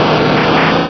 Cri de Tortank dans Pokémon Rubis et Saphir.